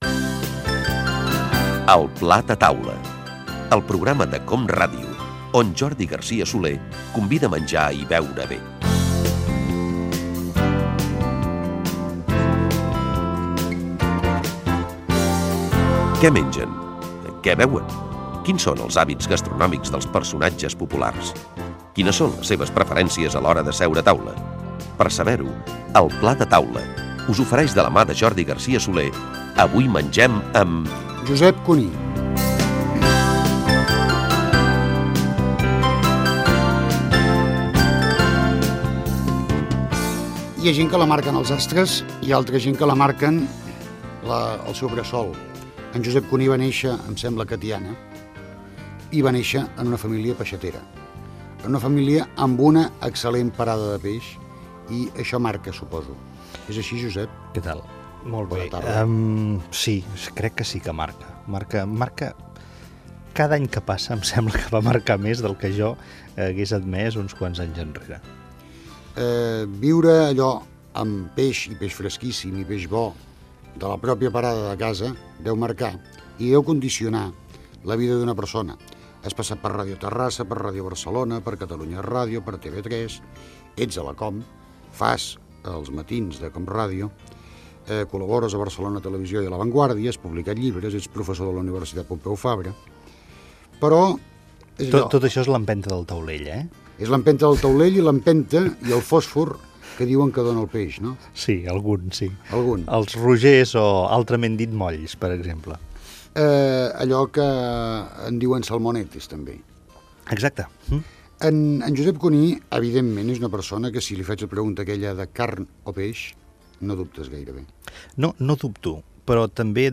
Careta del programa, presentació i fragment d'una entrevista sobre gastronomia amb el periodista i presentador Josep Cuní.